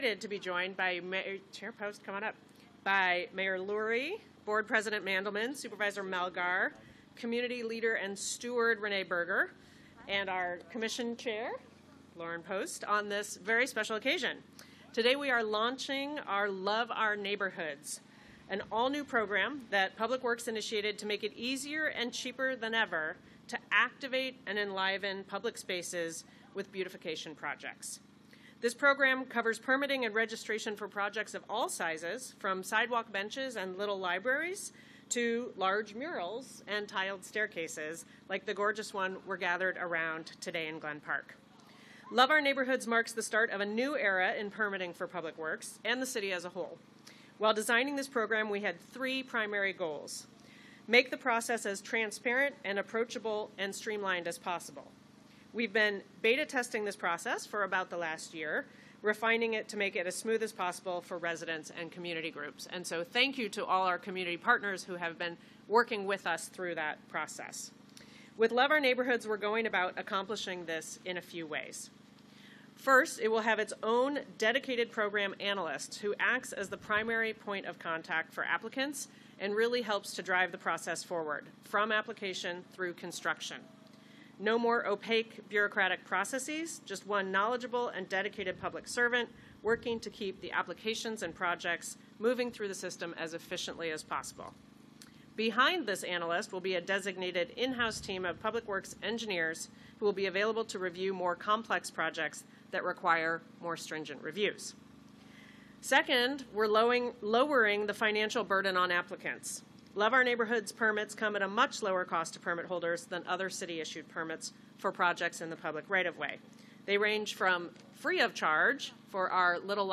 Mayor's Press Conference Audio Podcast